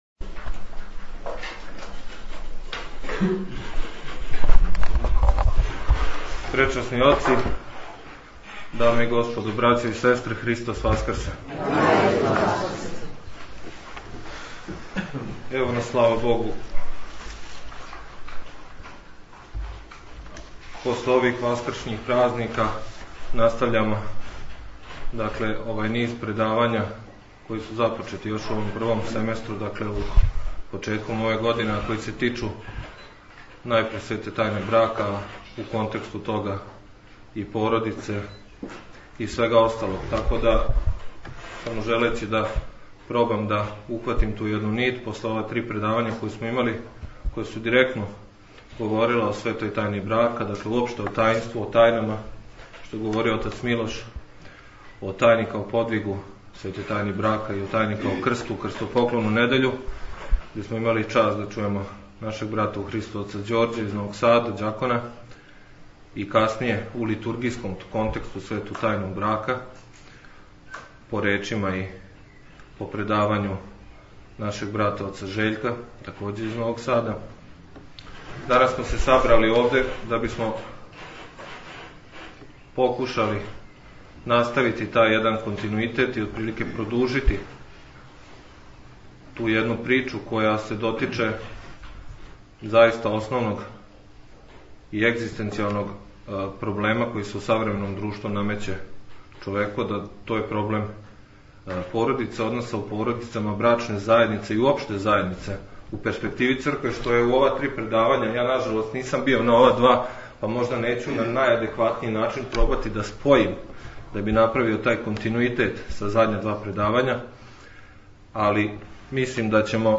Звучни запис предавања